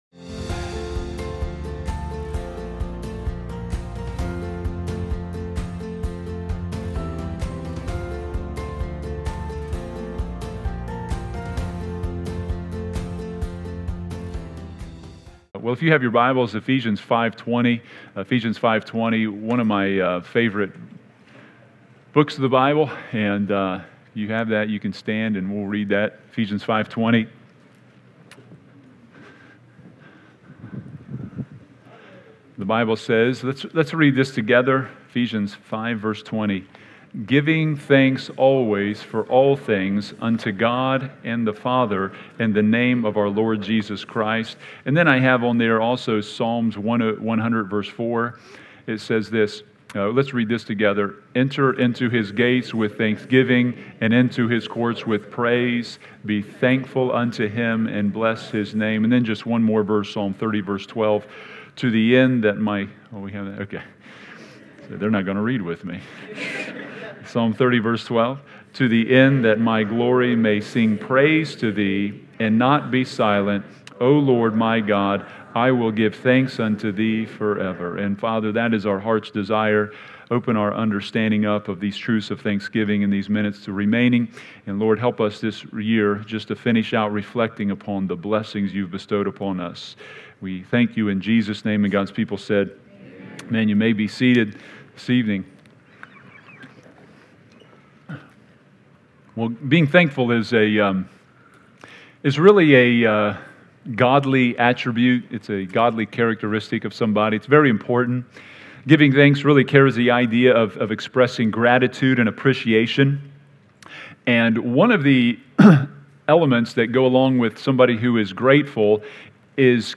Be Thankful | Thanksgiving Service